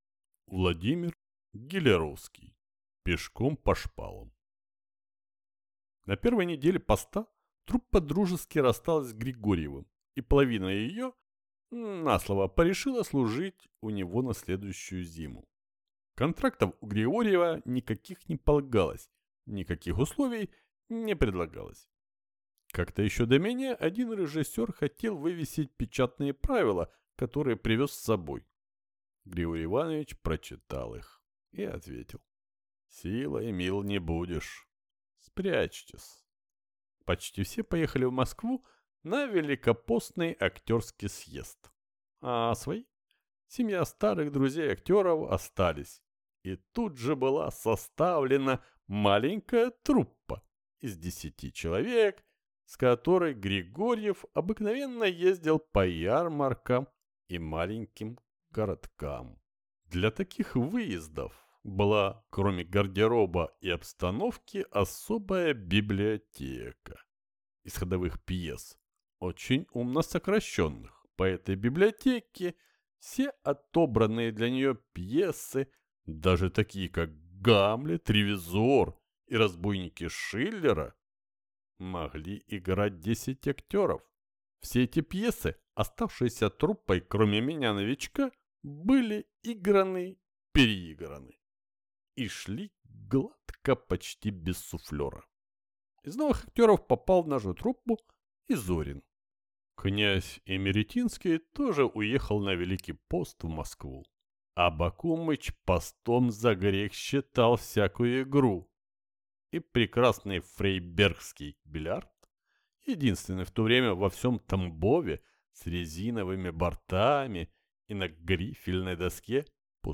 Аудиокнига Пешком по шпалам | Библиотека аудиокниг